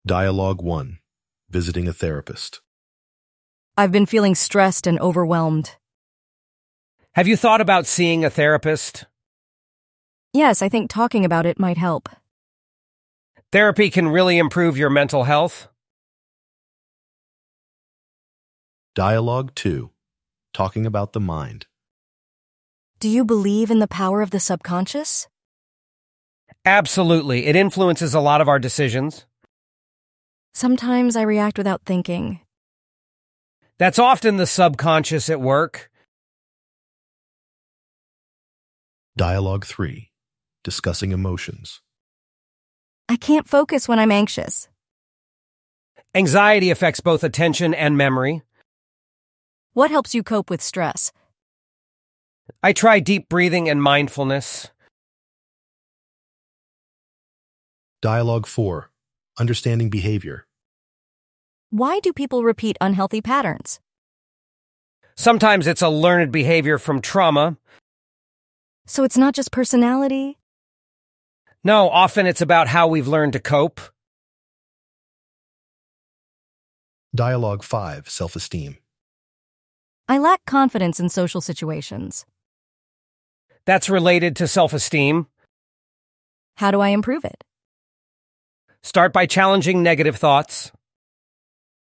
Pliki audio MP3 z nagraniami słów i dialogów do pobrania oraz na platformie
92_Psychological_Vocabulary_Understanding_the_Mind_and_Behavior_dialogi.mp3